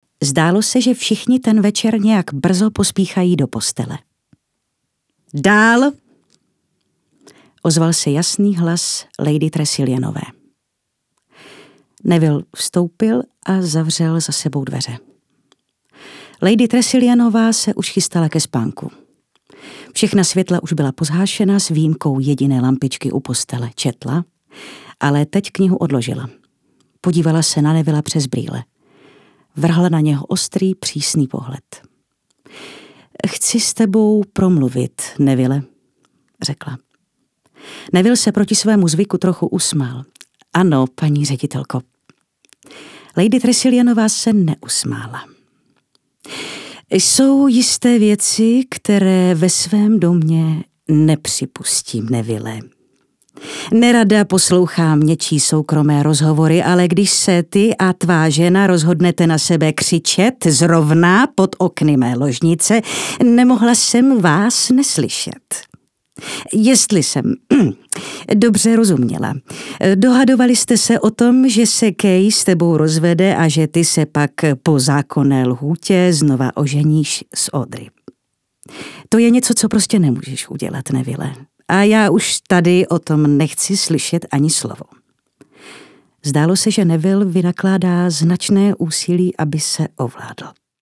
Dabing:
ukázka 1  / audio kniha